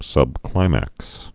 (sŭb-klīmăks)